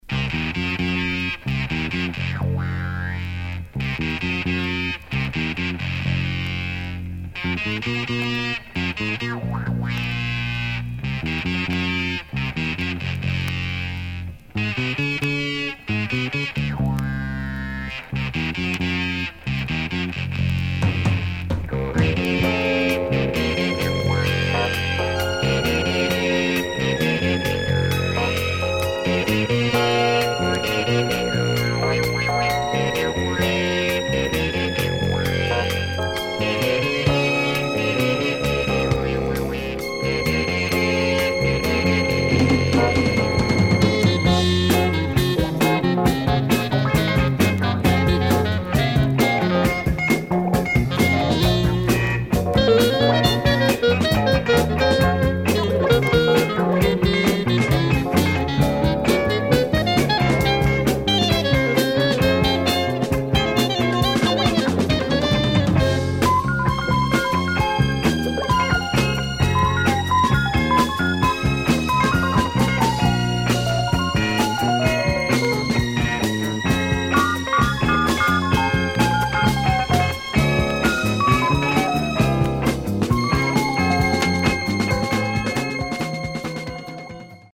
Deep jazz funk with Fender Rhodes.